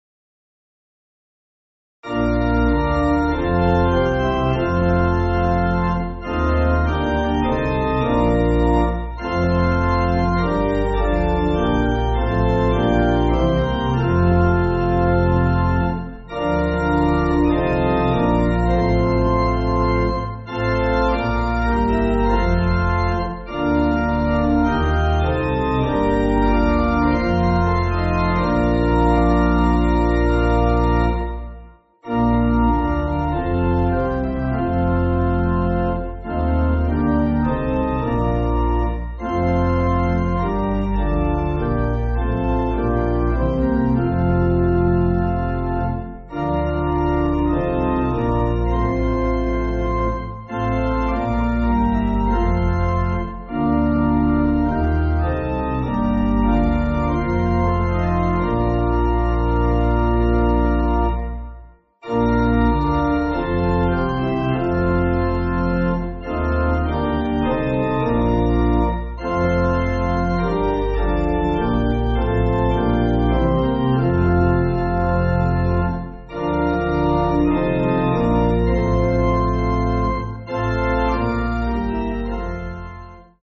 (CM)   4/Bb